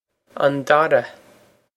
an dara on dorrah
Pronunciation for how to say
This is an approximate phonetic pronunciation of the phrase.